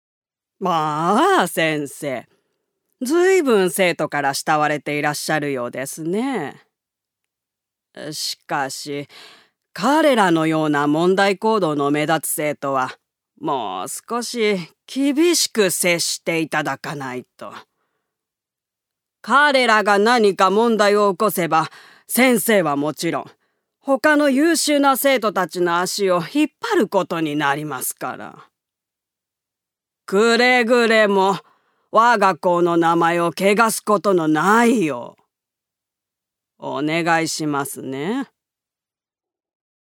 女性タレント
音声サンプル
セリフ１